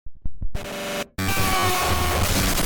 A-90_jumpscare.mp3